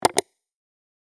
clicksound.mp3